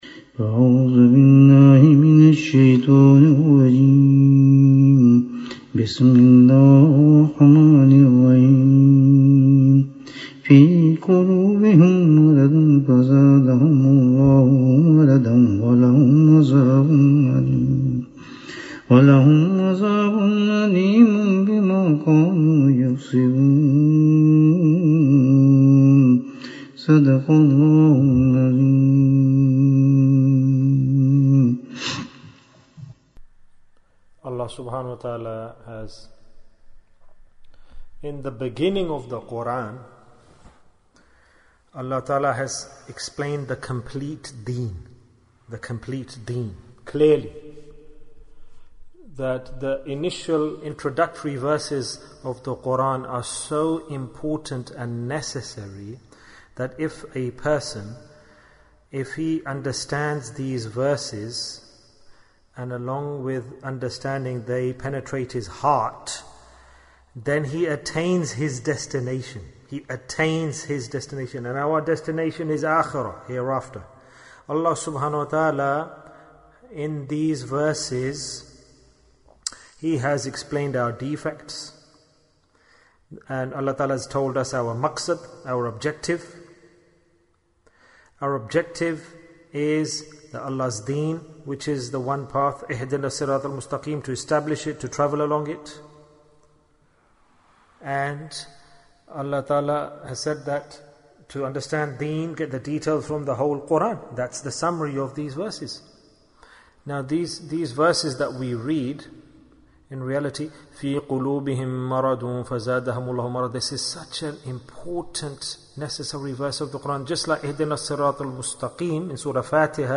What is the Reason for Going Astray? - Dars 11 Bayan, 48 minutes19th June, 2020